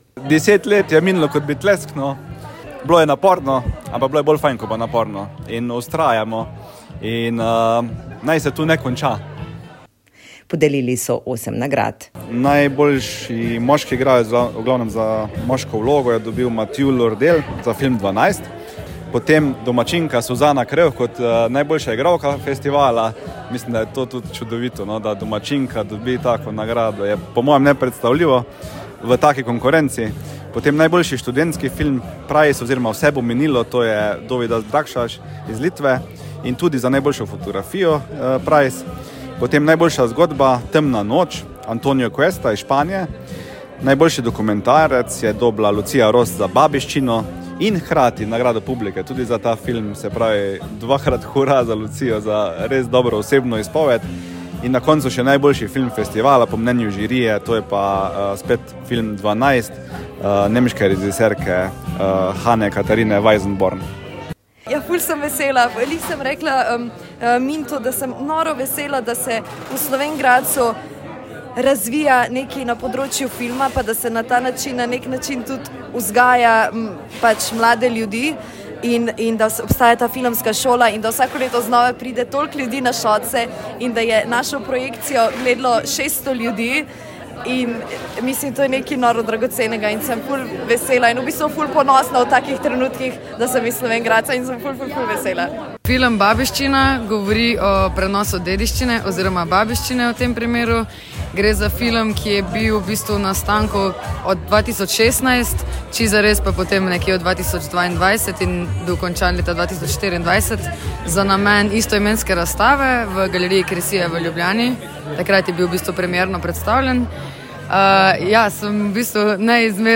dve nagrajenki